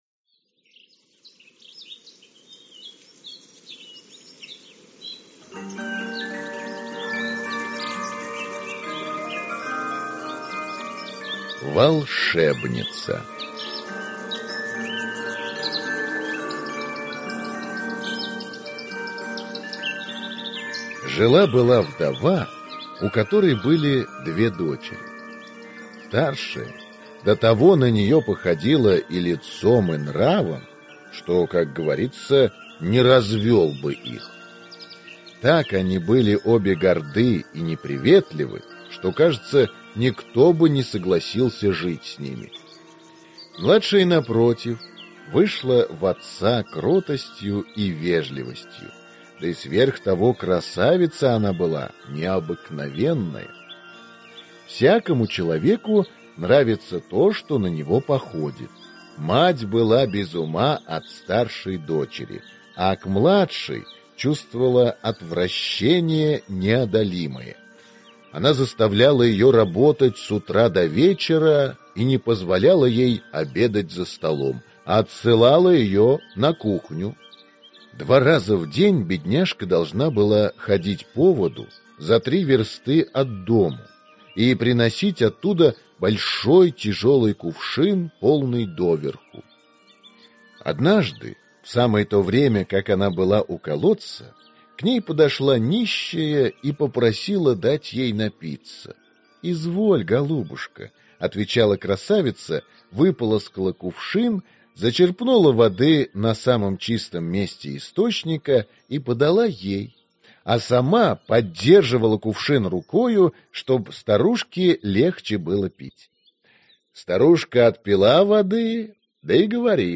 Aудиокнига
Читает аудиокнигу